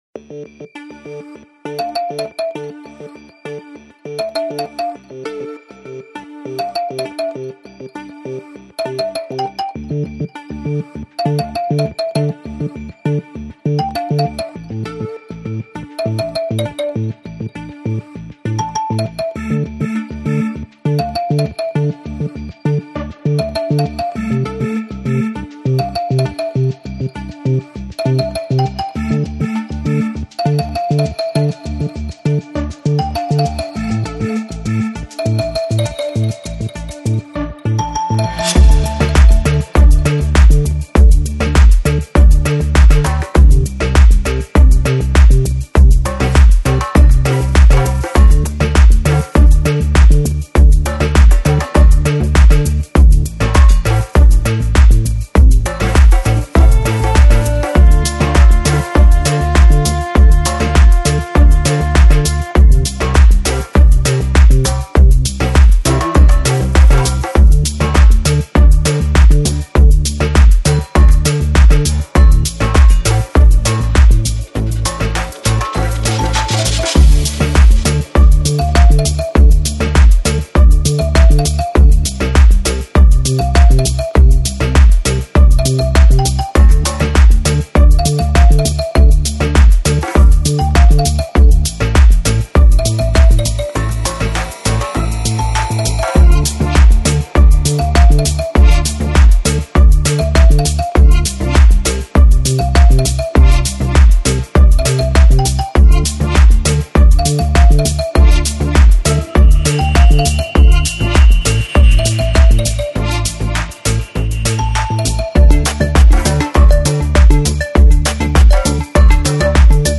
Жанр: Progressive House, Deep House, Downtempo, Afro House